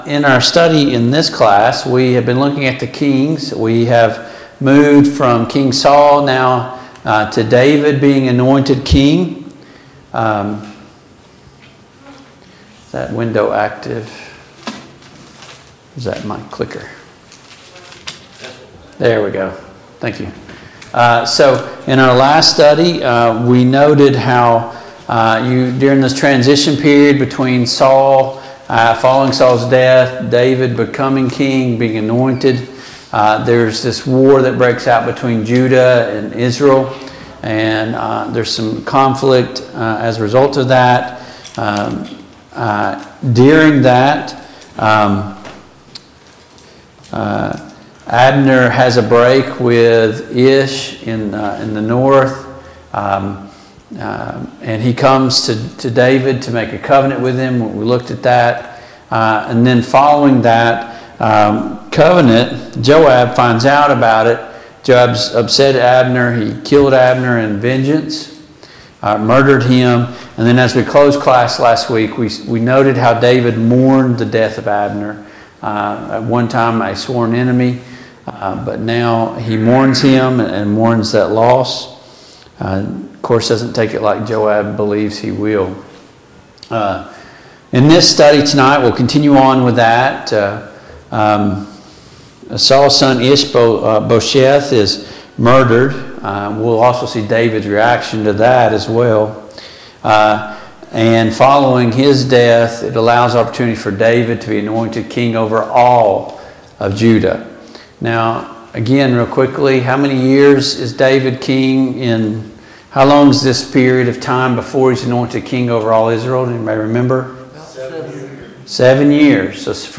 The Kings of Israel Passage: II Samuel 4, II Samuel 5 Service Type: Mid-Week Bible Study Download Files Notes « 1.